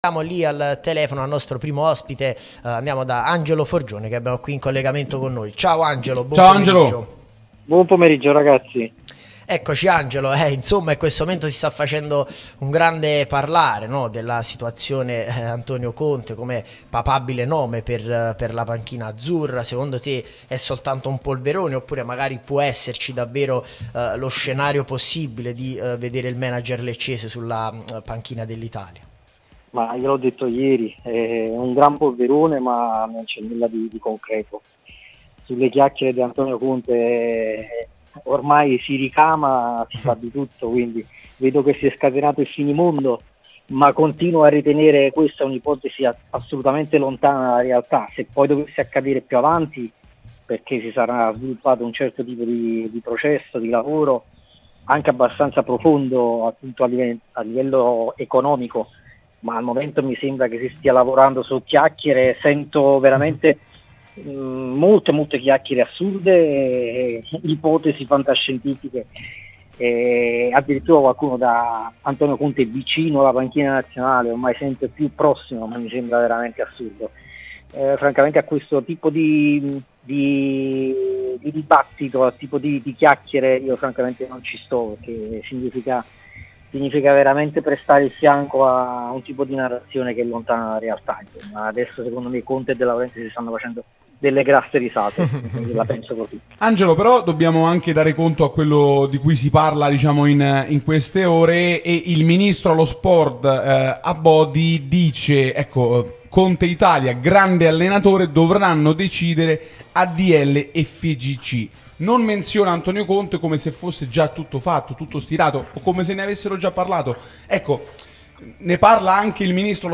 Le Interviste